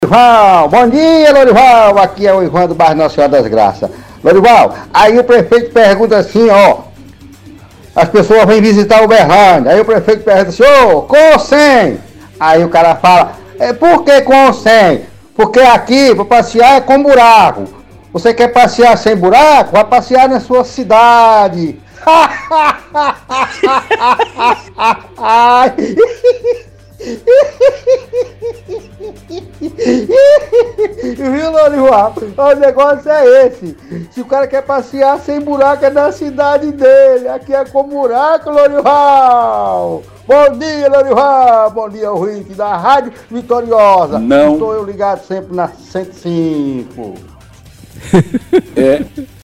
-Ouvinte debocha sobre quem quer visitar Uberlândia se quer com ou sem buracos, diz que cidade está cheia de buracos.